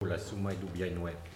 Elle provient de Saint-Gervais.
Locution ( parler, expression, langue,... )